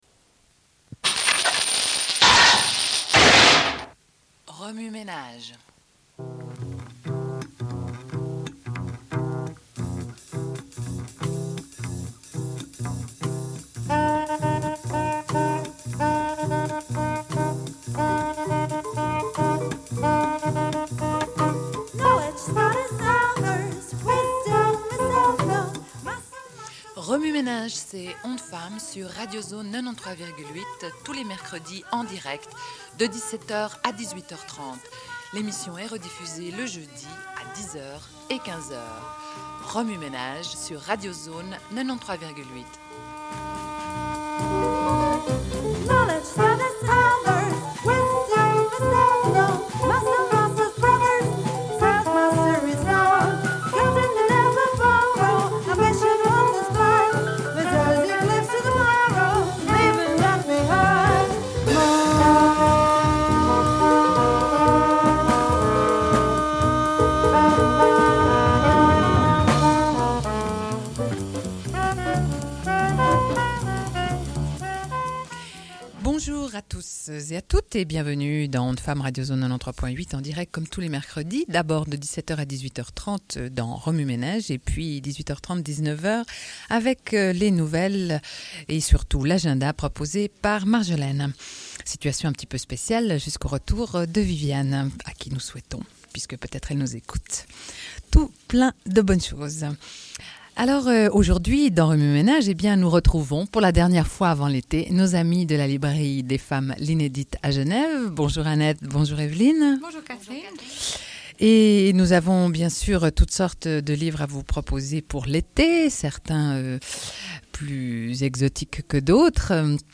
Une cassette audio, face A31:16